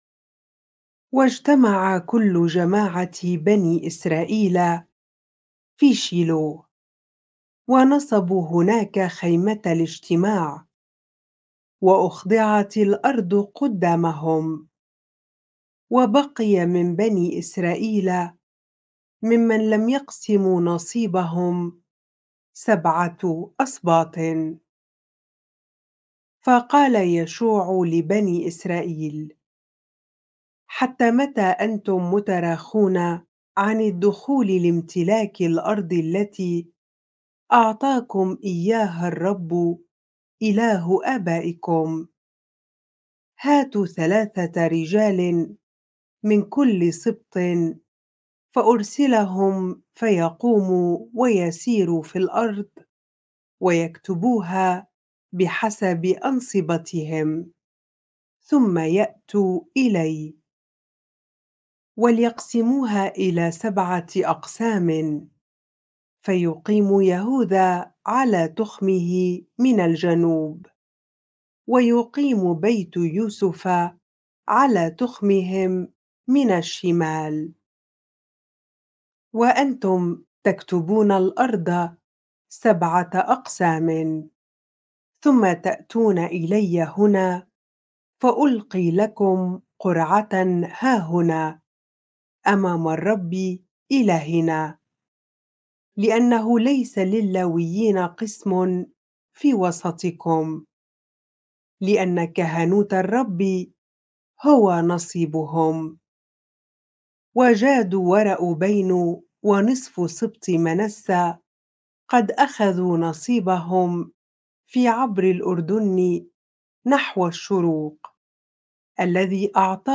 bible-reading-joshua 18 ar